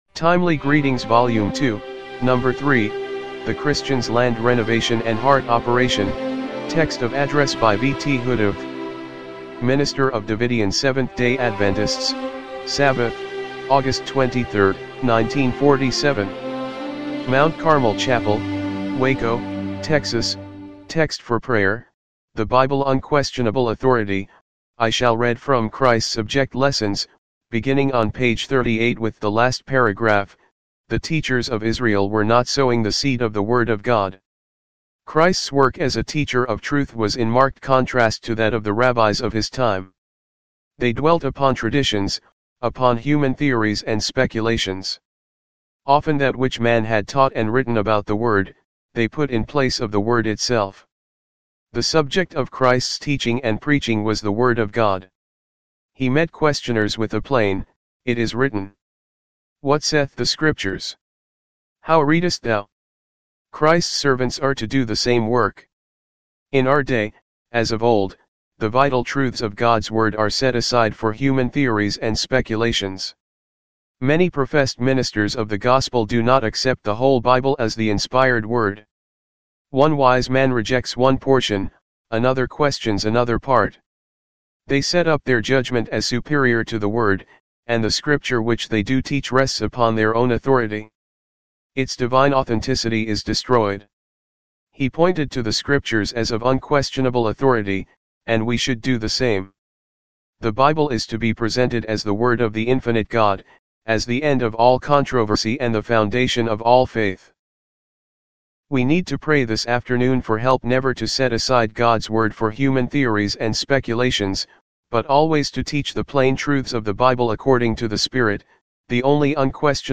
1947 MT. CARMEL CHAPEL WACO, TEXAS
timely-greetings-volume-2-no.-3-mono-mp3.mp3